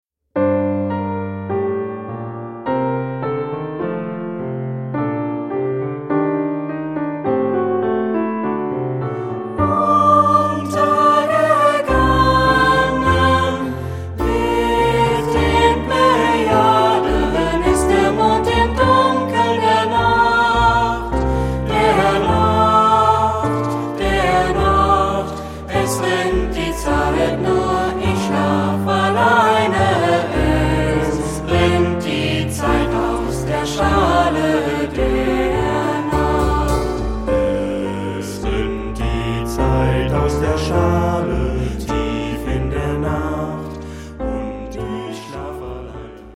Canon ; Jazz choral
mélancolique ; léger ; coulant
Ad libitum (3 voix Ad libitum )
Tonalité : sol mineur